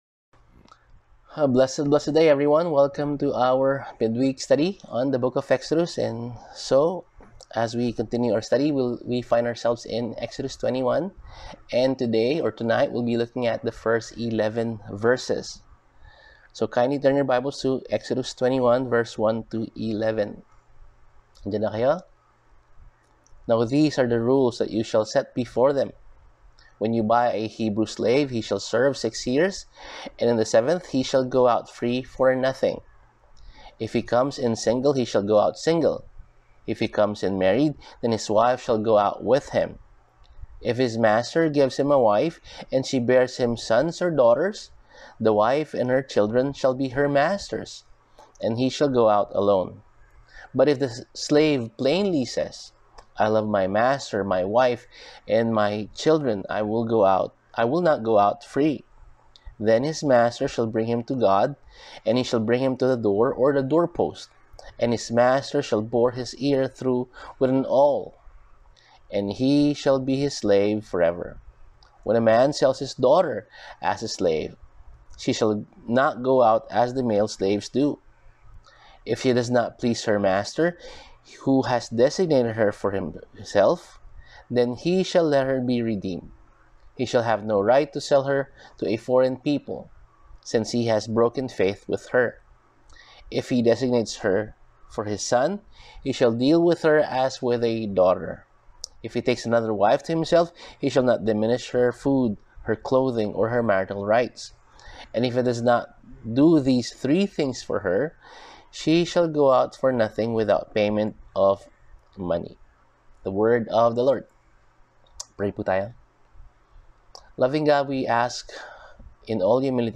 Service: Midweek Sermon